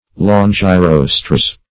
Longirostres \Lon`gi*ros"tres\, n. pl. [NL., fr. L. longus long